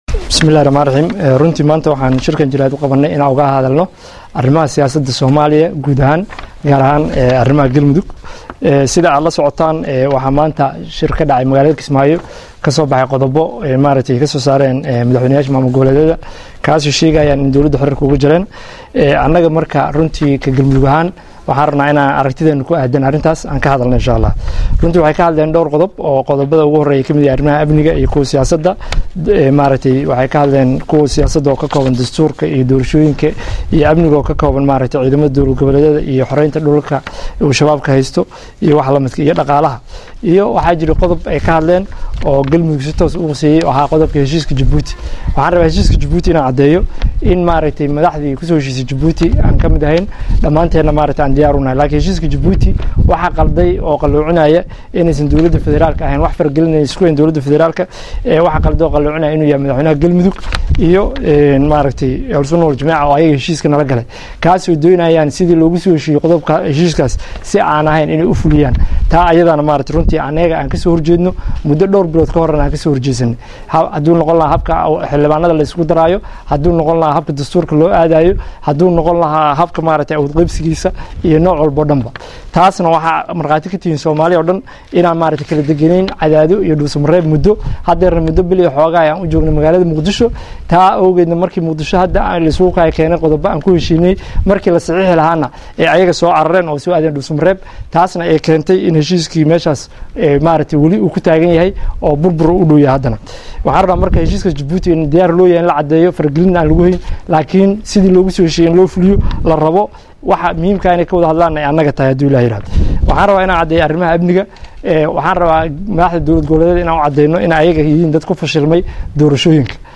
Baydhabo Somaliya-{WMN} Madaxweyne ku xigeenka maamulka Galmudug Maxamed Xaashi Cabdi Carabey oo maanta Warbaahinta kula hadlay Magaalada Caabudwaaq ee Gobolka Galgaduud ayaa waxa uu ka hadlay qodobadii ka soo baxay Shirka golaha Iskaashiga dowlad goboleedyada dalka oo lagu soo gabegabeeyay Magaalada Kismaayo.
Hadaba waa kan shirkiii Jaraaid oo qabta ku xigeenka .
DHAGEYSO_-Shirkii-Jaraa_039id-ee-Madaxweyne-Ku-xigeenka-Galmudug-kaga-horyimid-Shirki-Kismaayo-.mp3